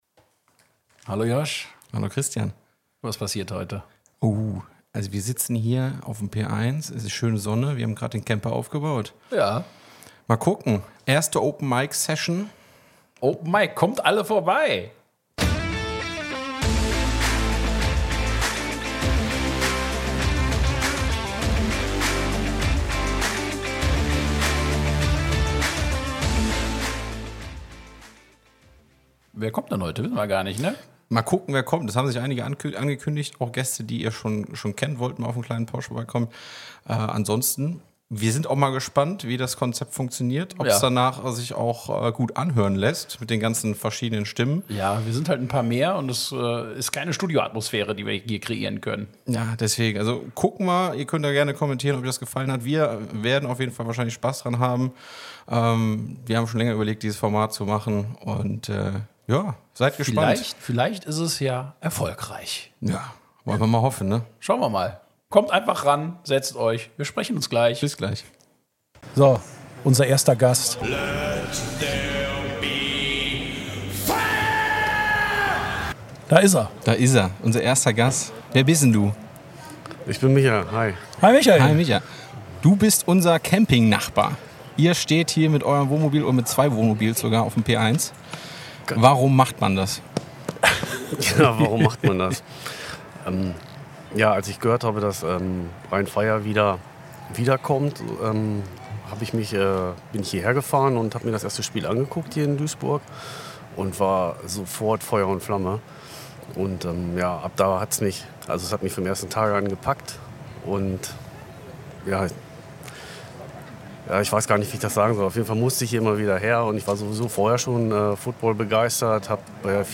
Open Mic Session vom P1 der Arena